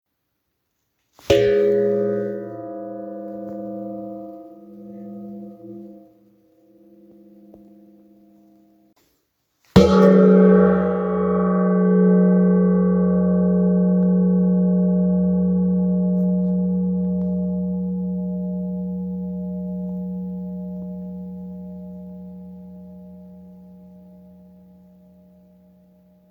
Instrument mit T oder G? Gong (links) und Tamtam (rechts) bei einer Messung im schalltoten Raum.
• Tamtam: Im Gegensatz zum Gong weist das Tamtam keine Ausbuchtung in der Mitte auf und hat vor allem keine klar hörbare Tonhöhe. Im Foto oben sehen Sie den Unterschied, in der Tondatei unten hören Sie ihn.
instrument-mit-t-tamtam.m4a